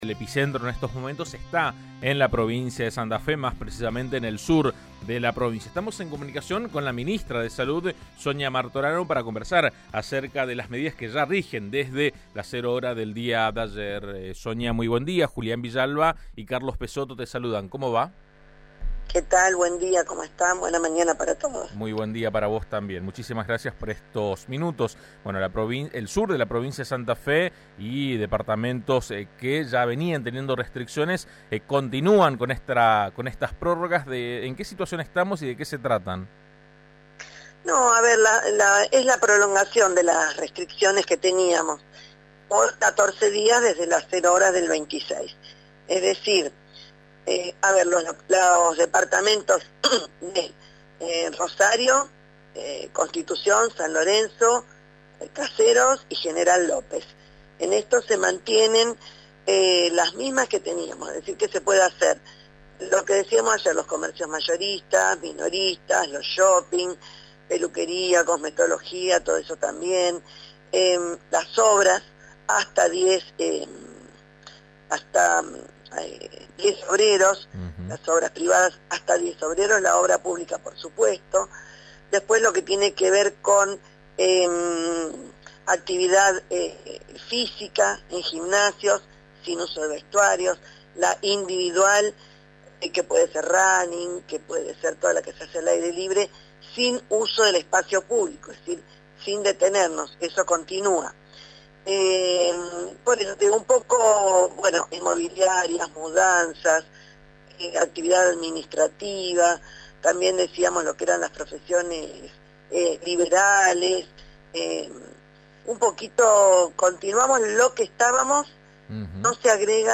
Rosario registró ayer menos de 500 casos nuevos de covid-19 y la provincia, menos de 1.500, mostrando una leve baja, aunque los números siguen siendo altos. AM 1330 habló sobre la situación epidemiológica actual con la ministra de salud Sonia Martorano.